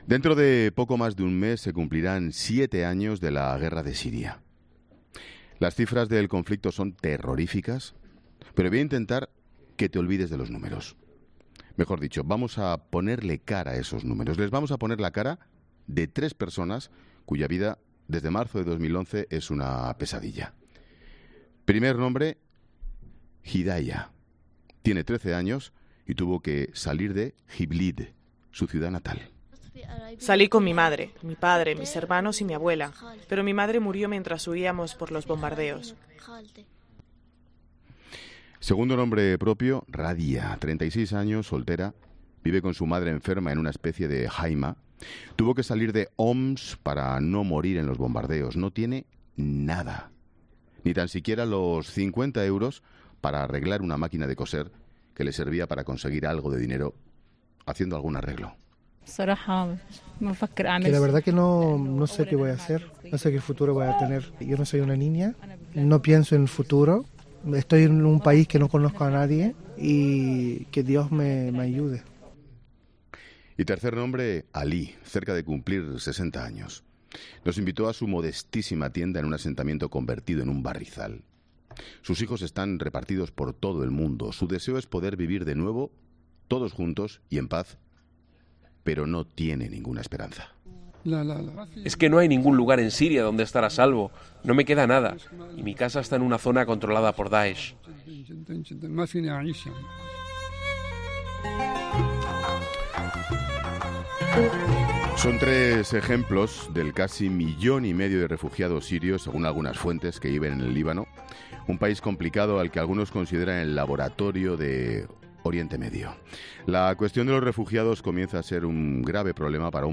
Hablamos con algunos de los militares españoles en el Líbano